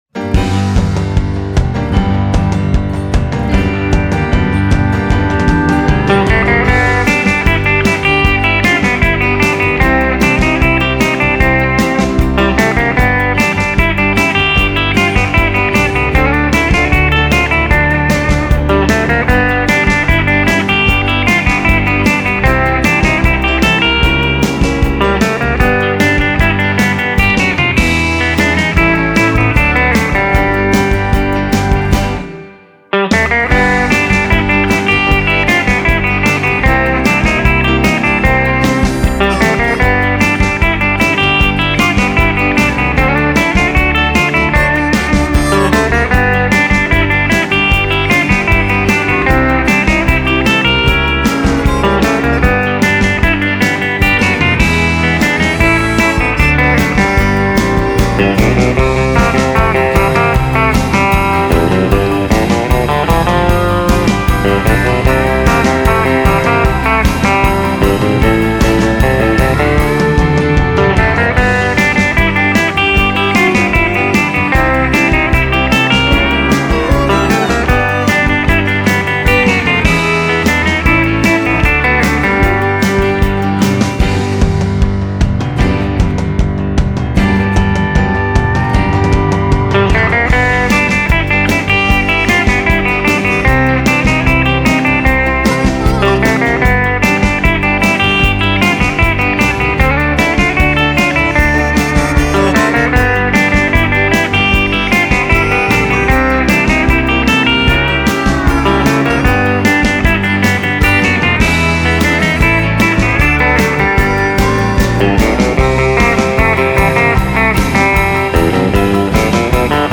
a fun happy tune